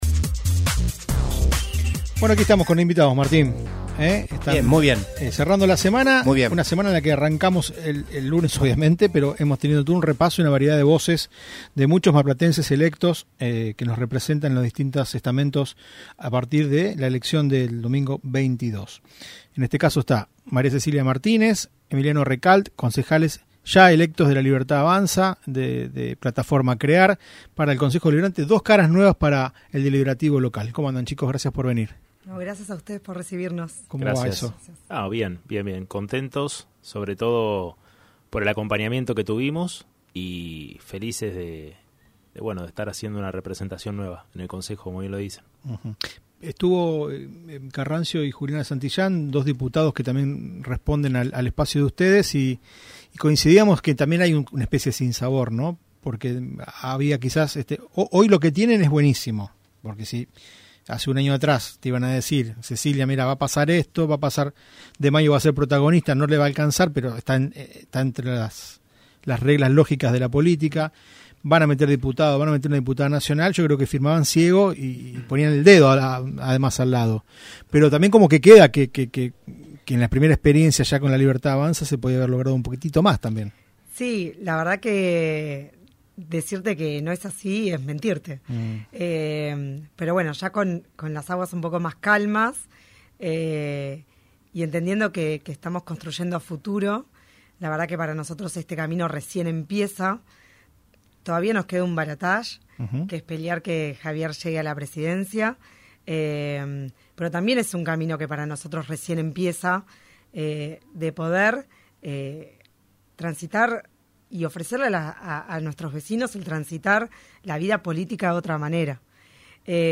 Lo expresó la concejal electa de LLA, Cecilia Martínez, quien estuvo en los estudios de "UPM" de Mitre (FM. 103.7) junto al otro edil electo del espacio, Emiliano Recalt.